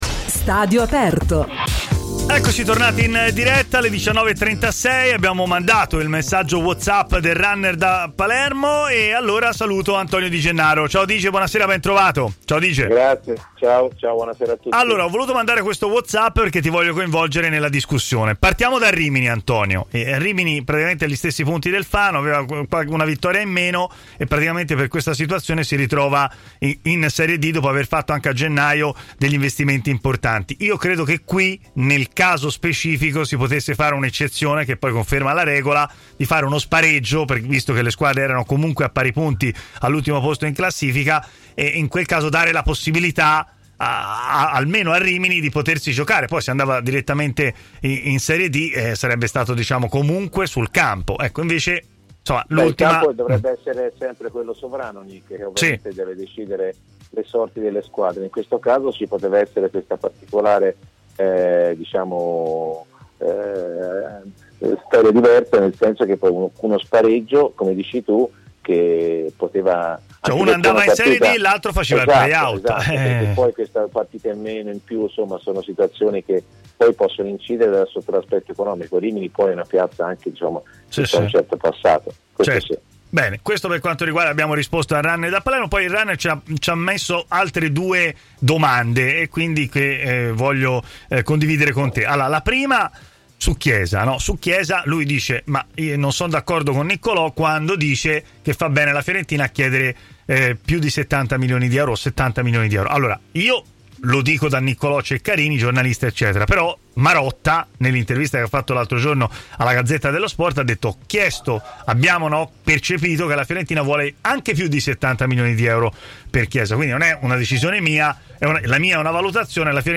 Antonio Di Gennaro, ex centrocampista e oggi commentatore sportivo, ha parlato a TMW Radio, intervenendo nel corso della trasmissione Stadio Aperto, condotta.